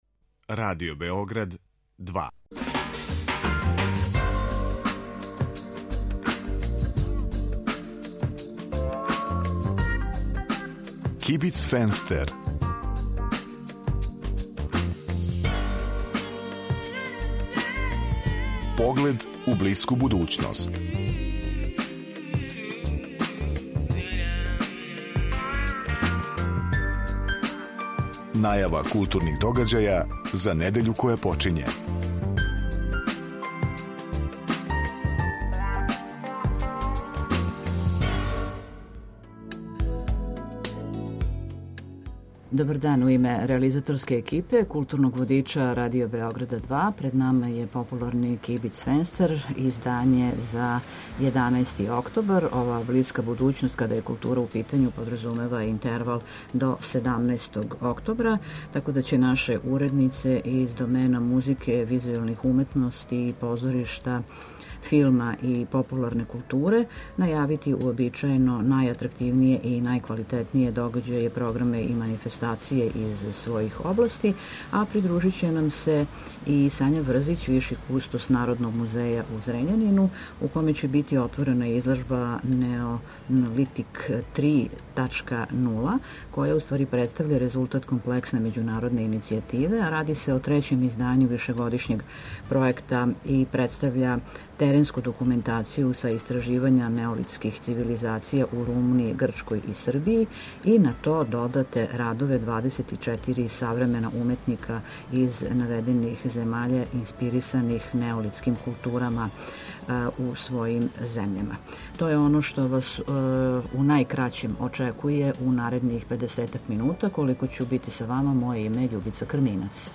Група аутора У некој врсти културног информатора за недељу која је почела, чућете аргументован избор и препоруку новинара и уредника РБ 2 из догађаја у култури који су у понуди у тој недељи. Свака емисија има и госта (госте), чији избор диктира актуелност – то је неко ко нешто ради у тој недељи или је везан за неки пројекат који је у току.